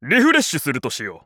Dr. Crygor's voice from the official Japanese site for WarioWare: Move It!
WWMI_JP_Site_Crygor_Voice.wav